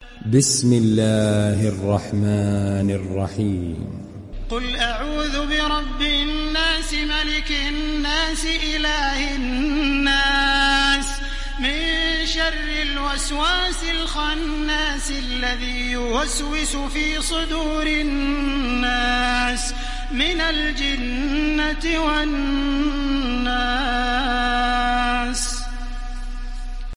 دانلود سوره الناس mp3 تراويح الحرم المكي 1430 روایت حفص از عاصم, قرآن را دانلود کنید و گوش کن mp3 ، لینک مستقیم کامل
دانلود سوره الناس تراويح الحرم المكي 1430